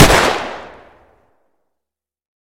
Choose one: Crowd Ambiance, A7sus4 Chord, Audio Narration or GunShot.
GunShot